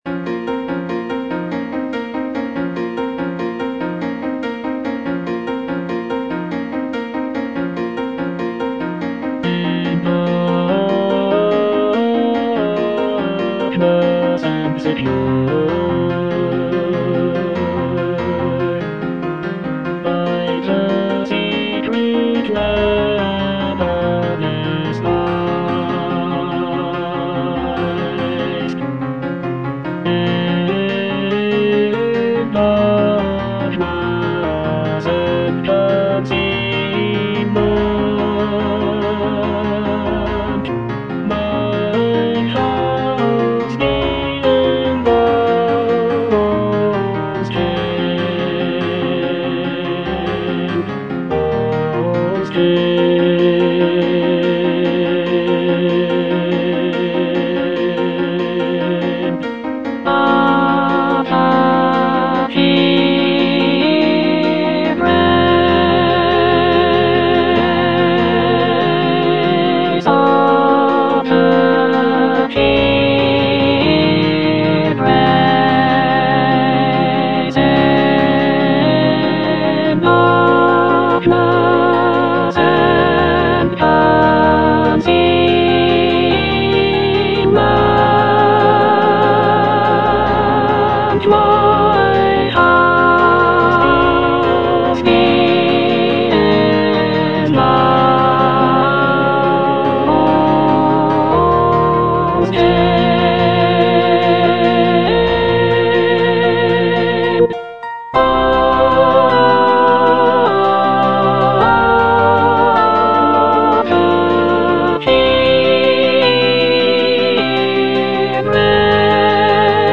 (soprano I) (Emphasised voice and other voices) Ads stop